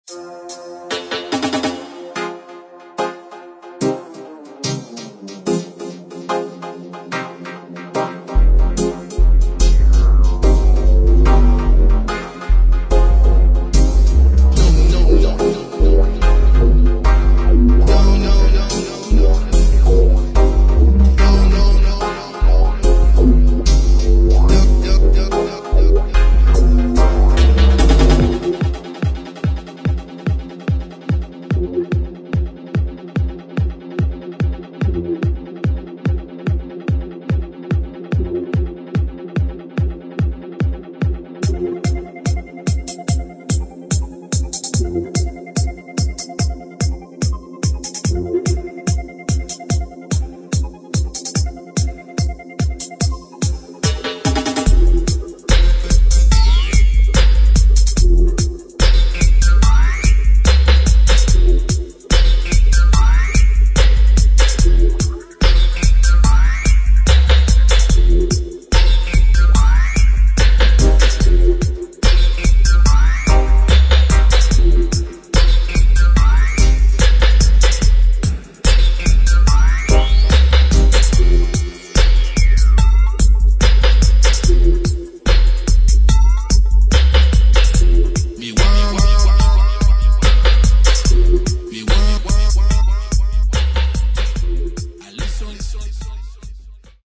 [ REGGAE / DUB / STEPPERS ]